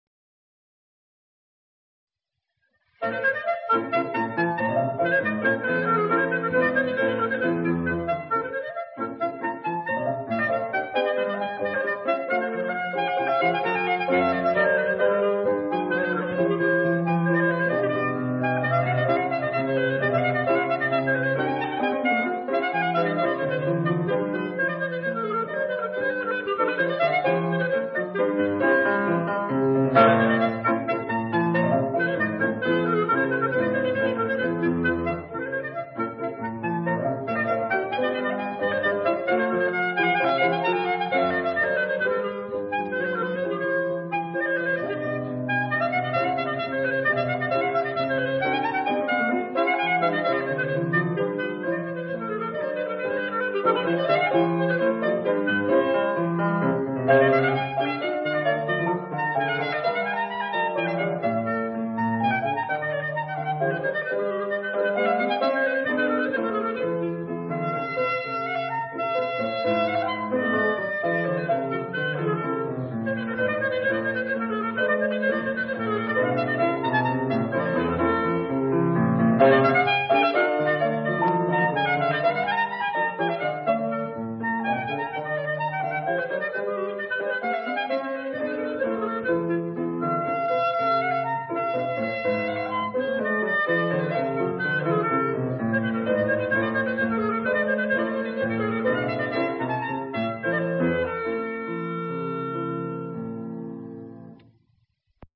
clarinetist